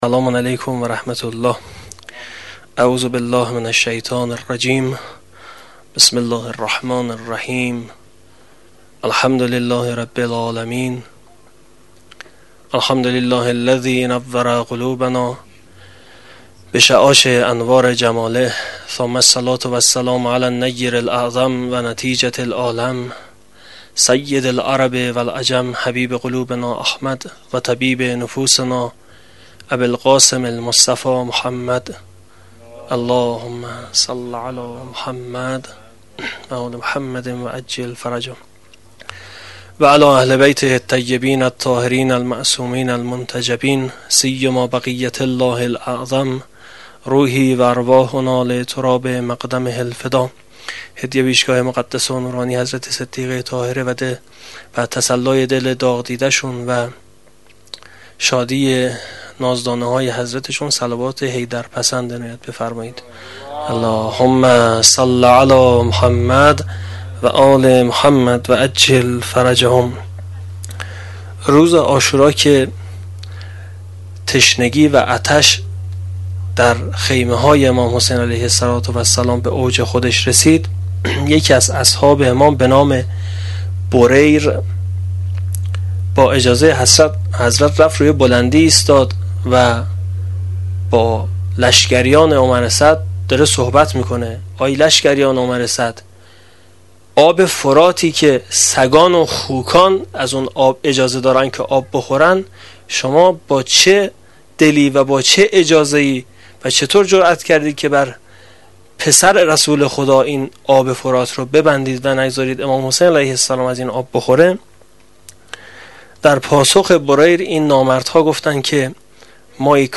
سخنرانی شب ششم محرم الحرام 1396